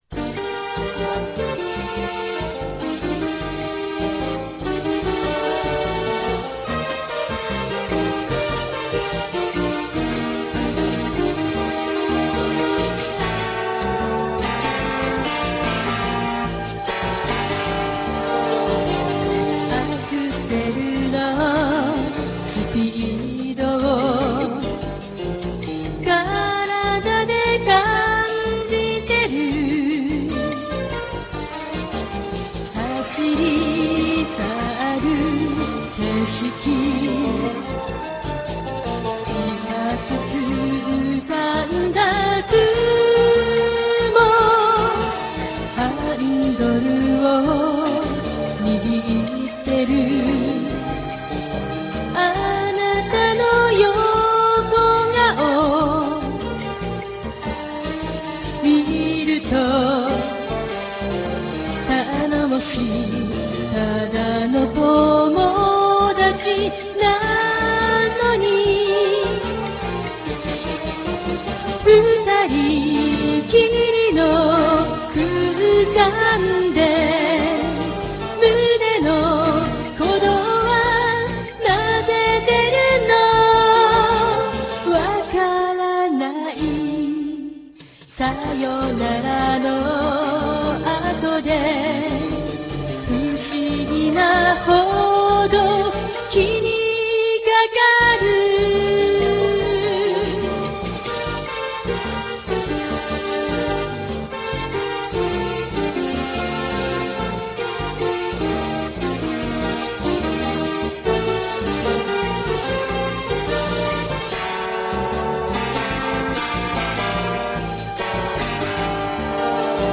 COMMENT◇作詞,作曲〜編曲,演奏〜録音,制作を全て自分一人で仕上げてます。
◇ボーカルはアマチュアですが，ライブハウスで唄ってます。